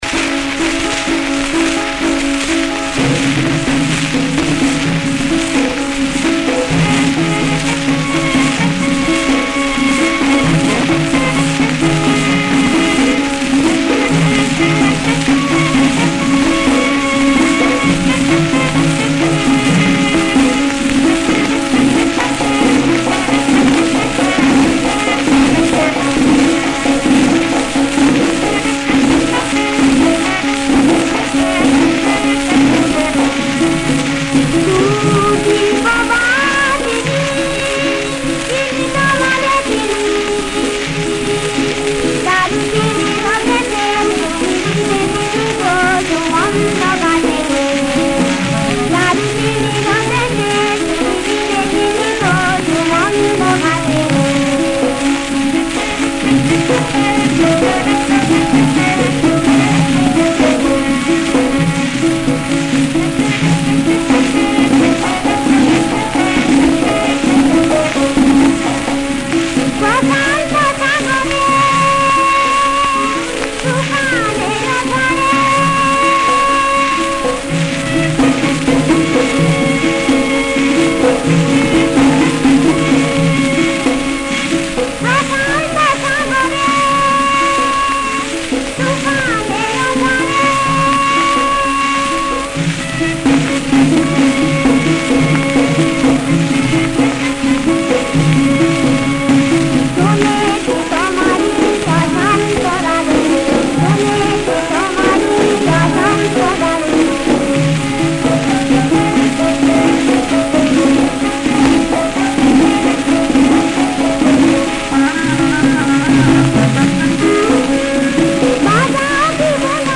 কিউবান সুর, তাল : কাহারবা
• সুরাঙ্গ: কিউবান নৃত্যের সুর
• তাল: কাহারবা
• গ্রহস্বর: পা